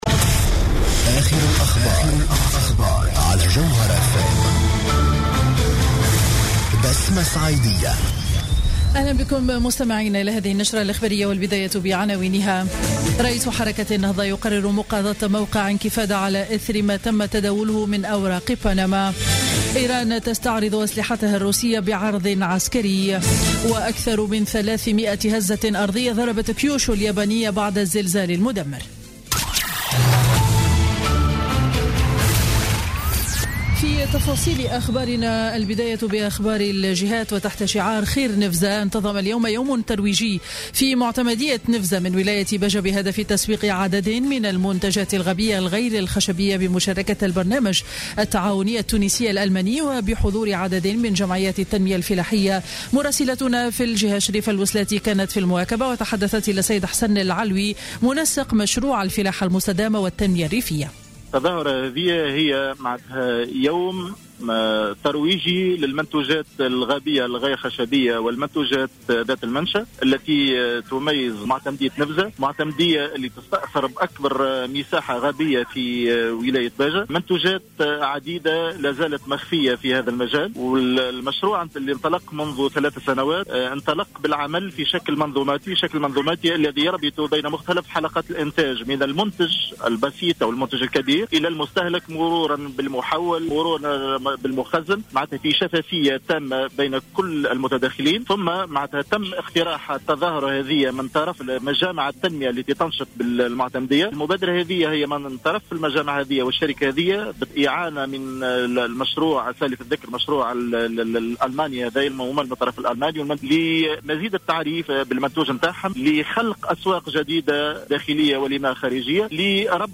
نشرة أخبار منتصف النهار ليوم الأحد 17 أفريل 2016